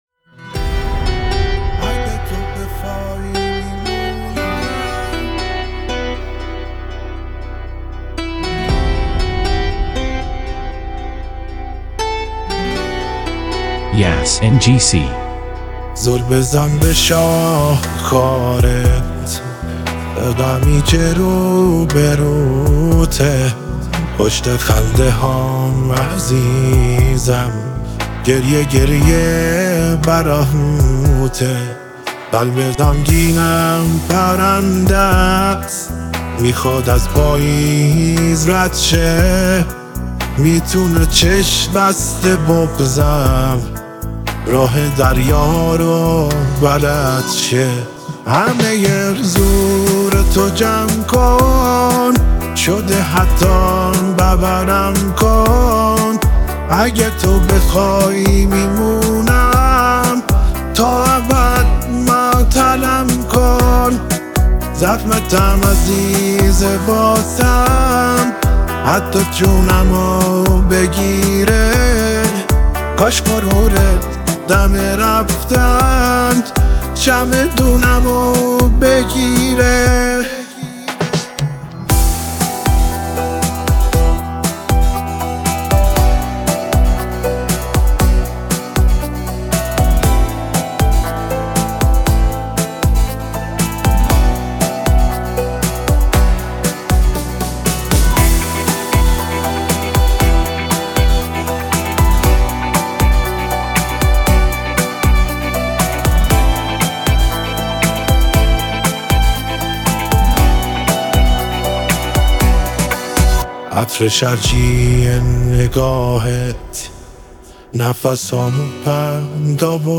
آهنگ احساسی